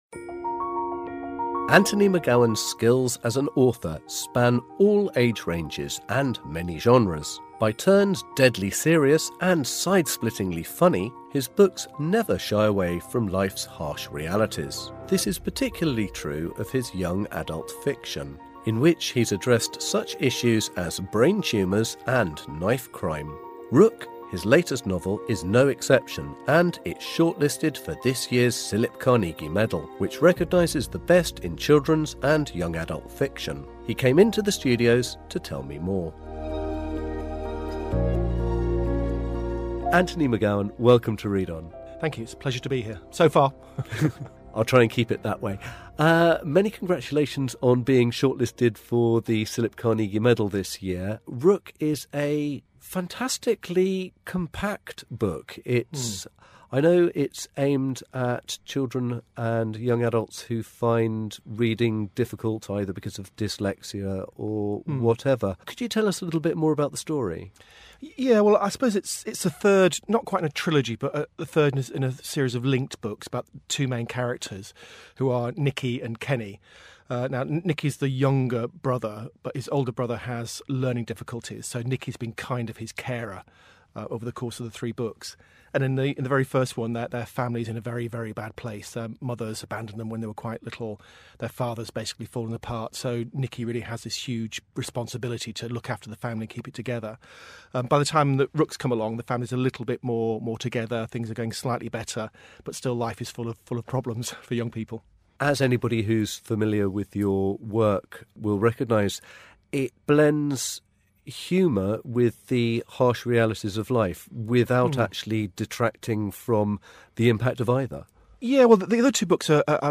Author Interview - Anthony McGowan